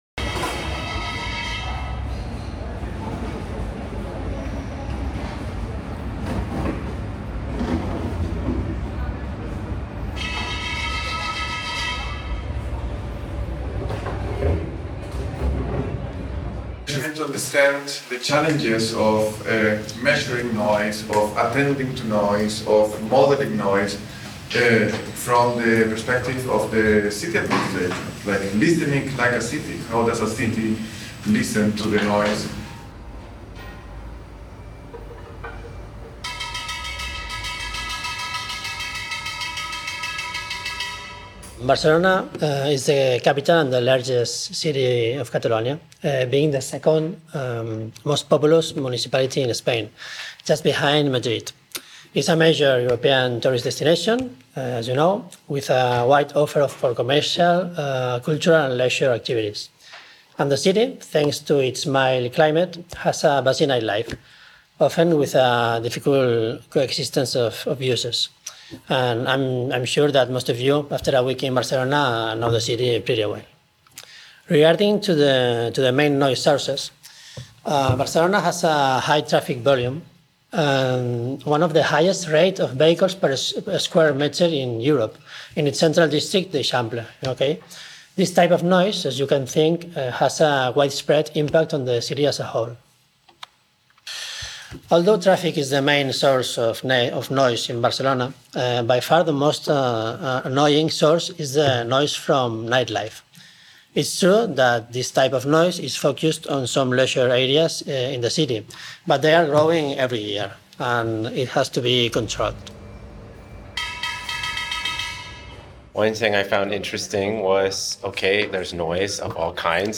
It includes sonic traces of Barcelona’s noise, and traces of the discussion that emerged out of the experiment on the last day.